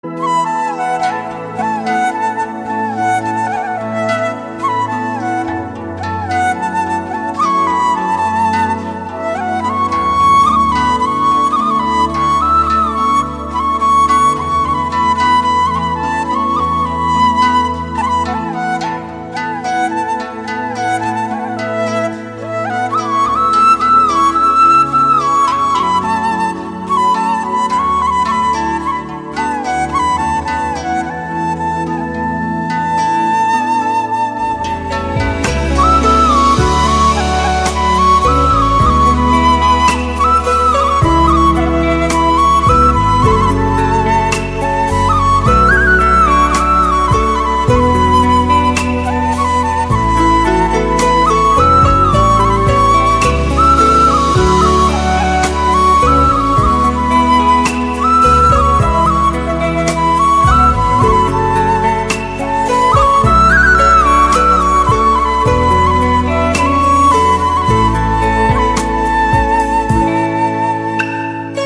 Nét hấp dẫn của bản phối Sáo Trúc